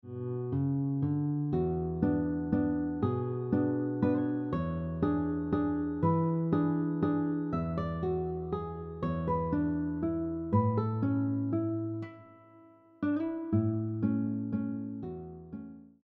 guitar audio track